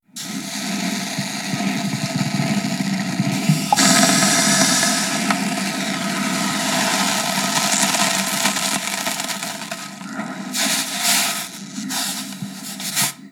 Agua hirviendo en un cubo metálico
Sonidos: Agua
Sonidos: Hogar